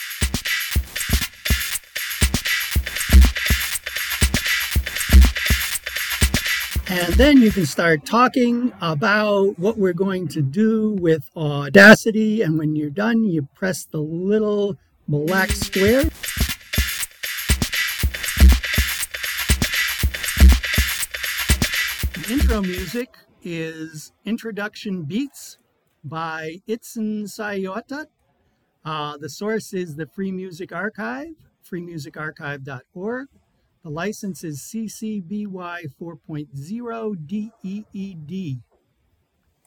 [Opening Music: Upbeat, futuristic synths]